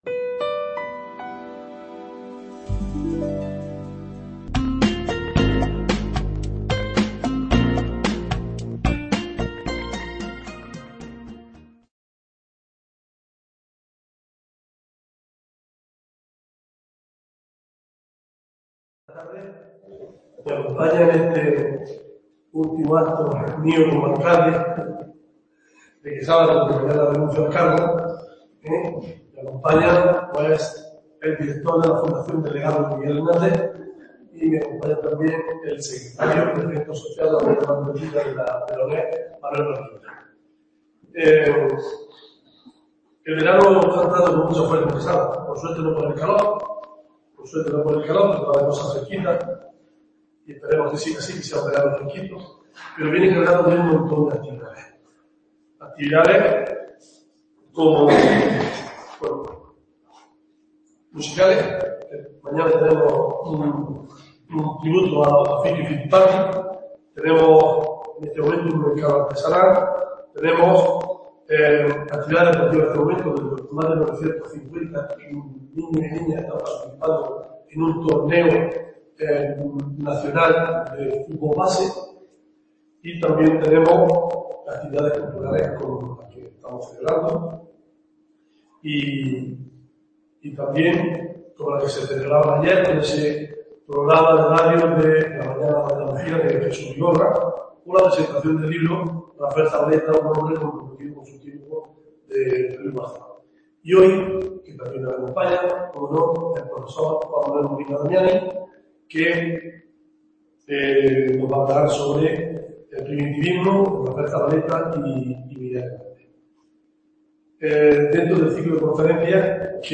imparte esta conferencia en el Museo Zabaleta (Quesada)